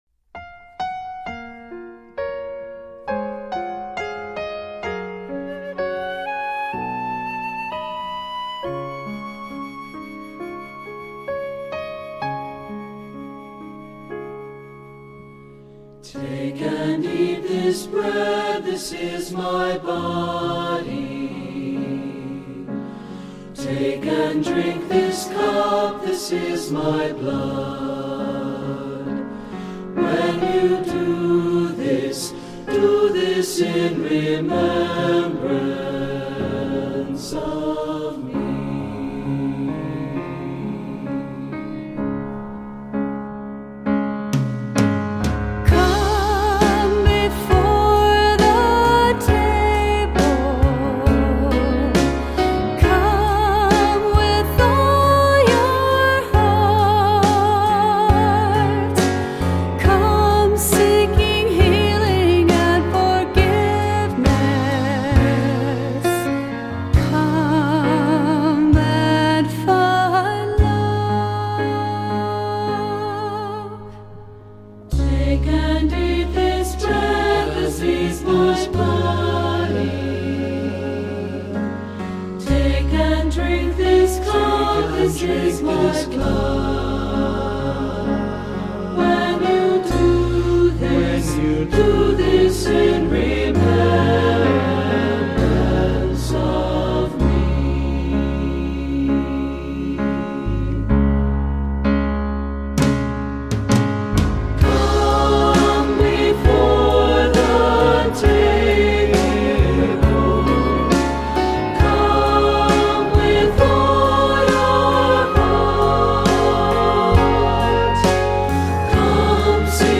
Voicing: Assembly, descant,SATB